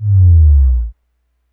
bass01.wav